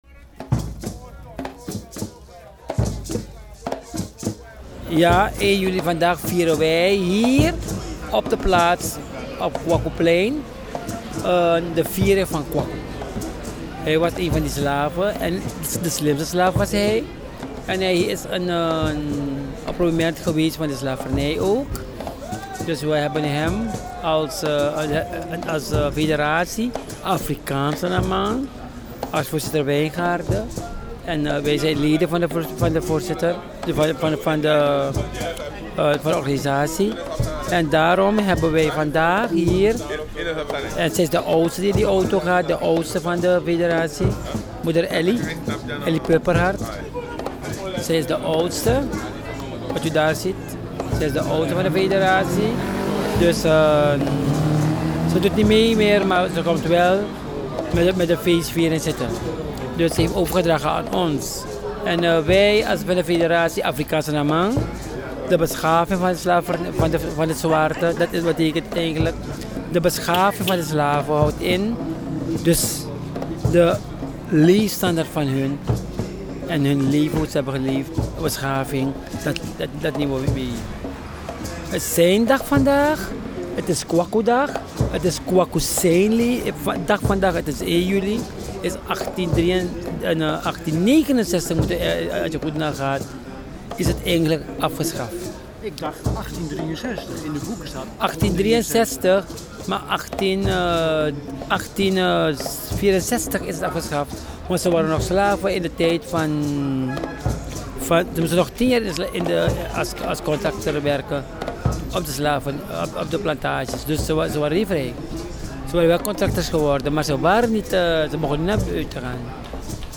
Al op het Kwakoeplein spreek ik iemand van de organisatie en ik vraag hem wat er deze dag op 1 juli gevierd wordt. Opvallend is dat hij van mening is dat 1863 niet juiste jaartal is, luister zelf:
Keti-Koti-viering-in-Paramaribo.mp3